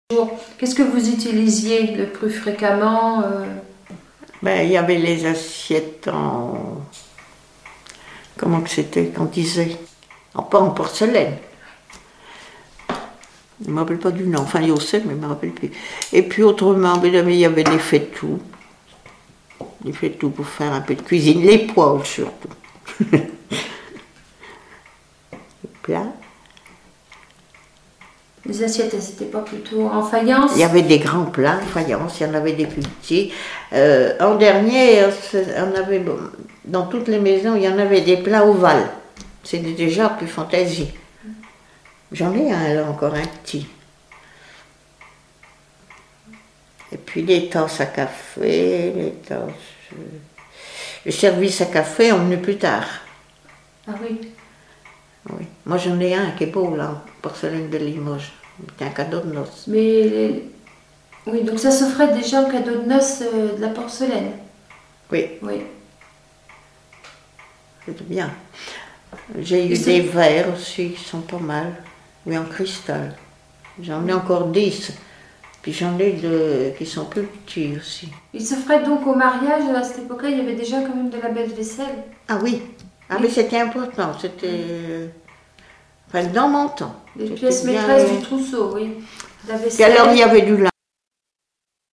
Témoignages relatifs aux noces et la vie à la ferme
Catégorie Témoignage